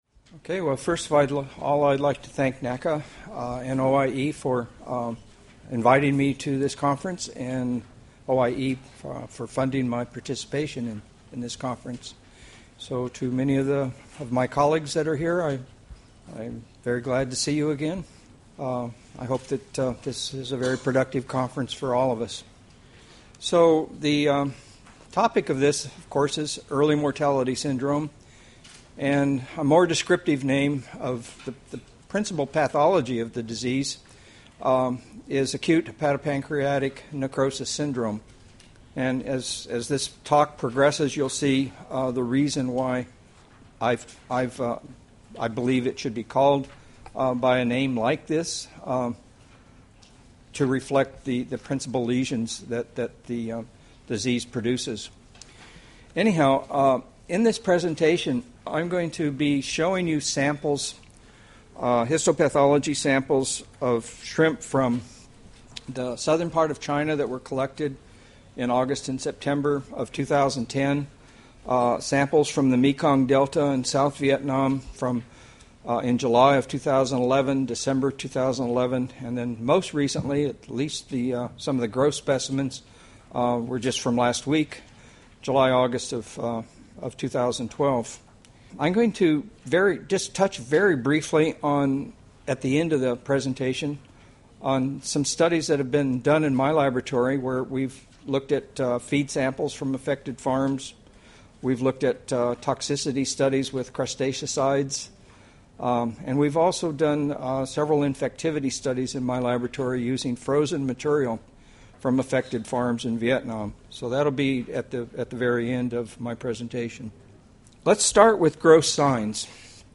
Presentation on the characterisation, distribution, impacts and case definition for acute hepatopancreatic necrosis syndrome.